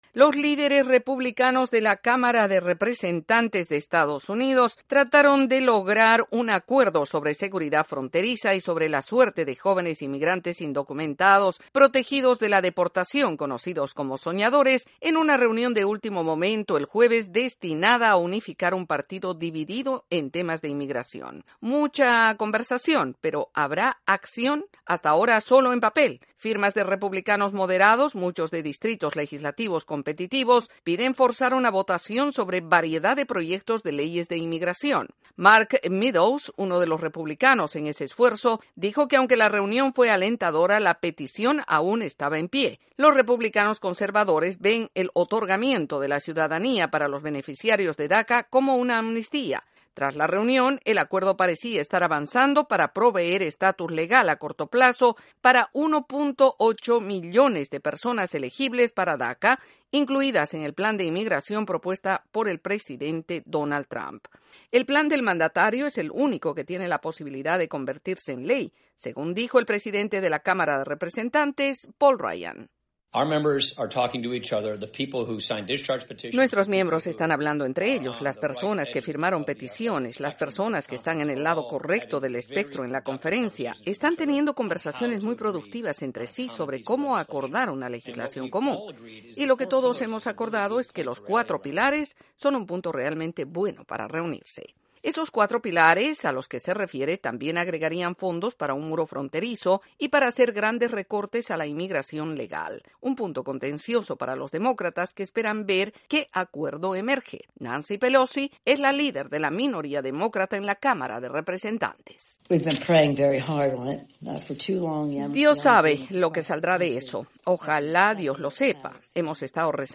En la Cámara de Representantes del Congreso estadounidense hay un creciente interés en promover la votación de los proyectos de ley sobre los “soñadores”. Desde la Voz de América en Washington DC informa